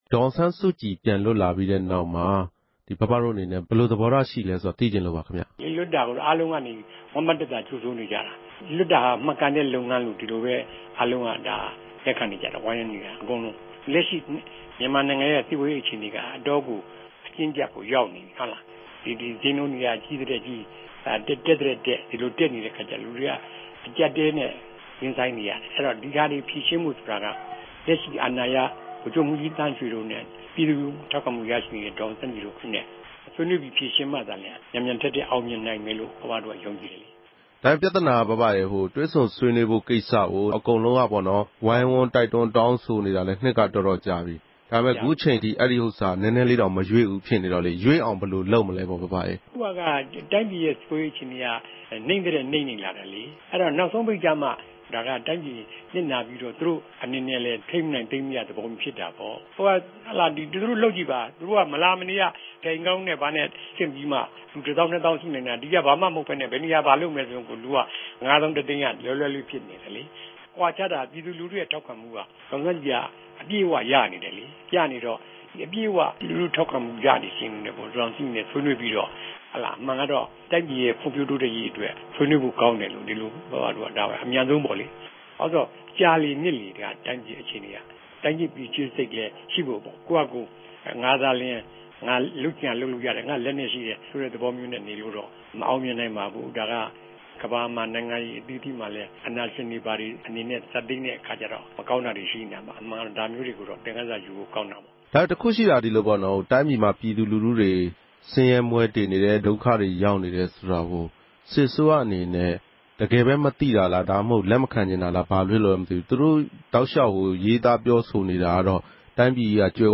ဒေါ်အောင်ဆန်းစုကြည် လွတ်မြောက်လာတာနဲ့ မြန်မာ့နိုင်ငံရေး အခြေအနေတွေနဲ့ ပတ်သက်ပြီး RFA က ဆက်သွယ်မေးမြန်းရာမှာ